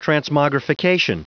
Prononciation du mot transmogrification en anglais (fichier audio)
Prononciation du mot : transmogrification